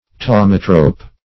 Thaumatrope \Thau"ma*trope\ (th[add]"m[.a]*tr[=o]p), n. [Gr.